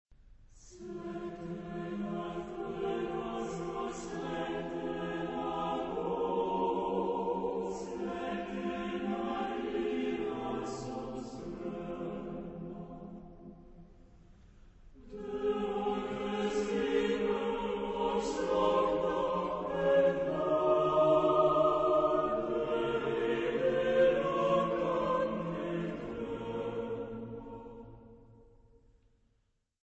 Genre-Style-Forme : Profane ; Lied
Caractère de la pièce : andantino
Type de choeur : SATB  (4 voix mixtes )
Tonalité : fa mineur